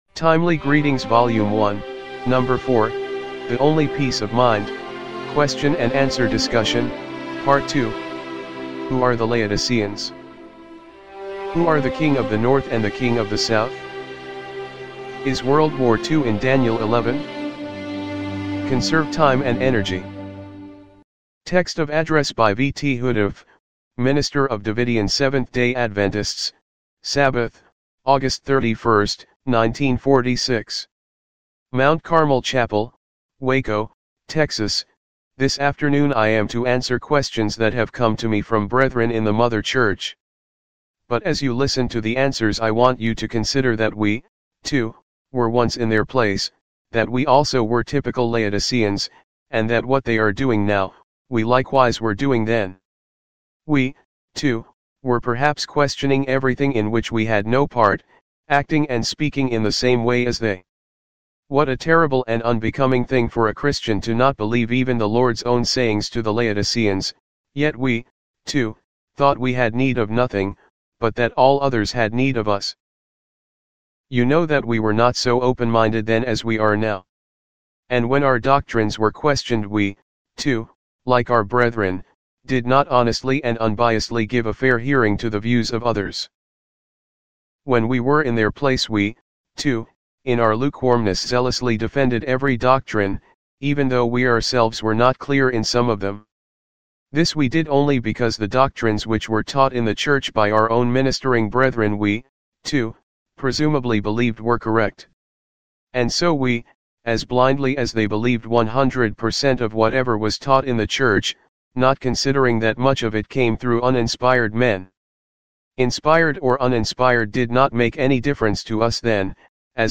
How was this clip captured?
timely-greetings-volume-1-no.-4-mono-mp3.mp3